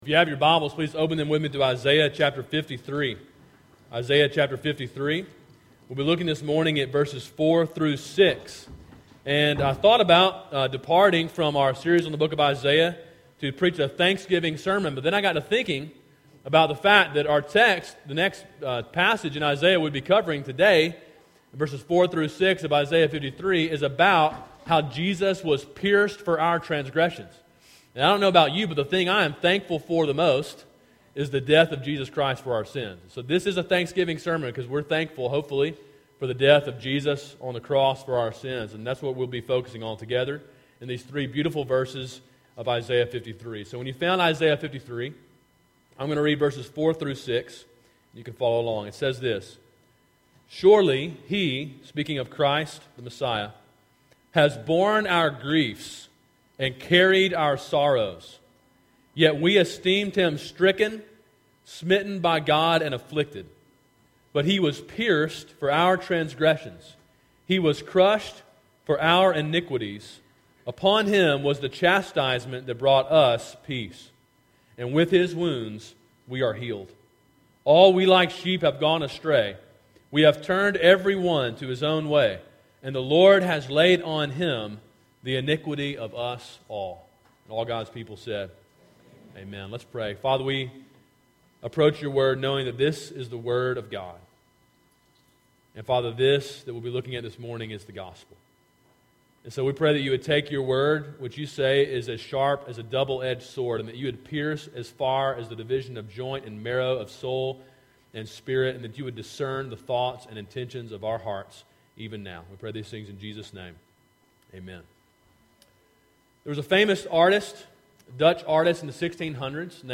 Sermon: “He Was Pierced” (Isaiah 53:4-6) – Calvary Baptist Church